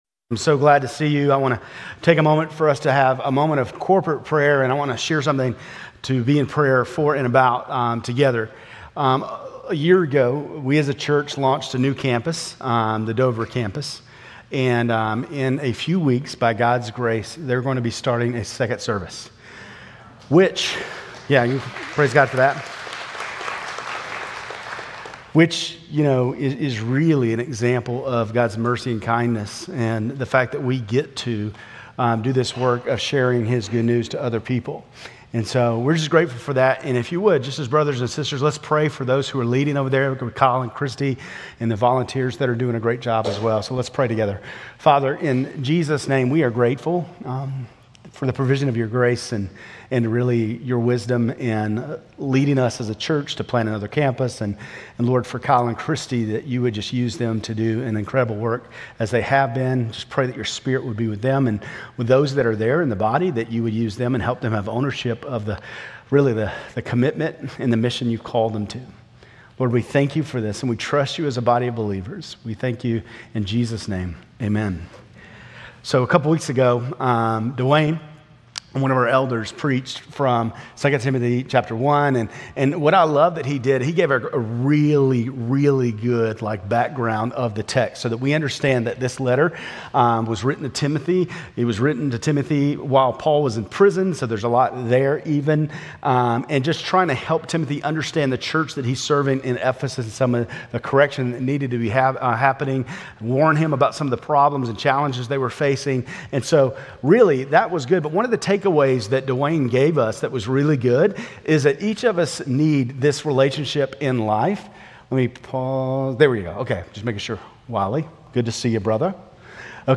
Grace Community Church Lindale Campus Sermons 8_24 Lindale Campus Aug 24 2025 | 00:36:13 1x 00:00 / 00:36:13 Subscribe Share RSS Feed Share Link Embed